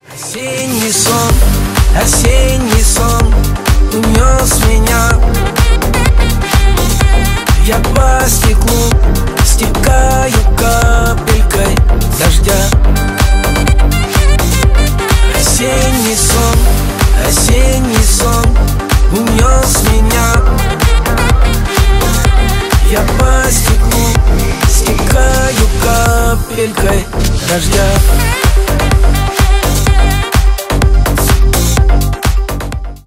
Ремикс
клубные